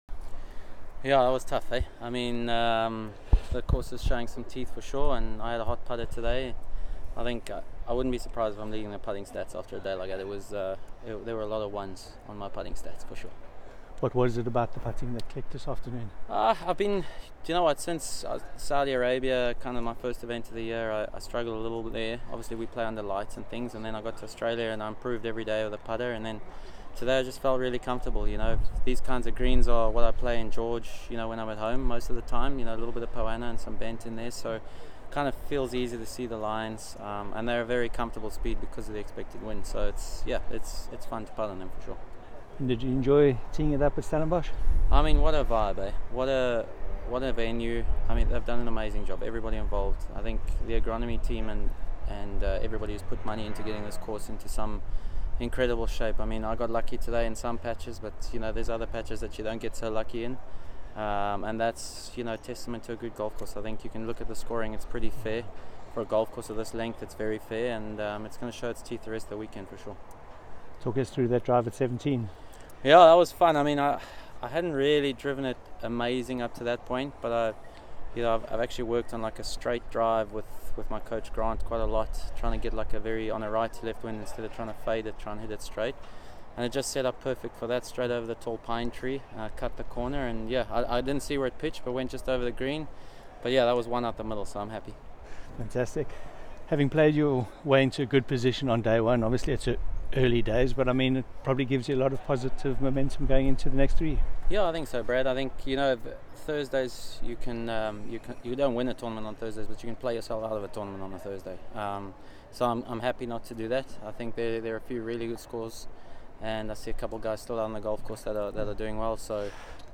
Dean-Burmester-Interview.m4a